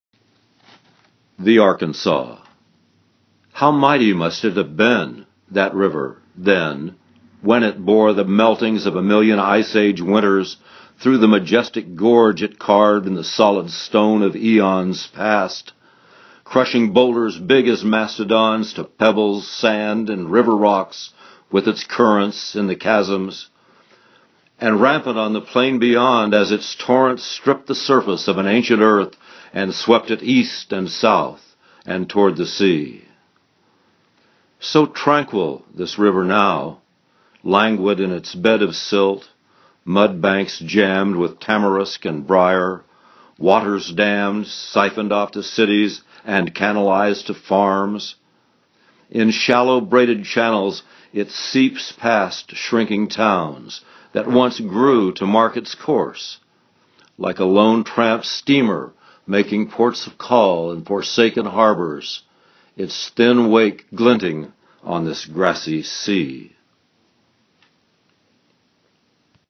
reading of this poem